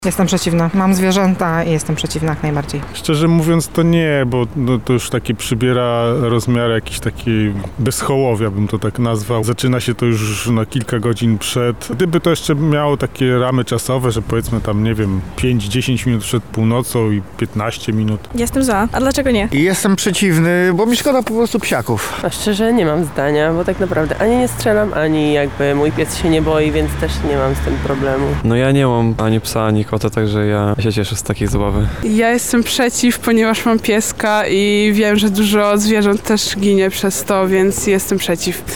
Spytaliśmy lublinian o stanowisko co do rozrywki „na pełnej petardzie”.
SONDA
sonda-fajerwerki-tak-czy-nie_mixdown.mp3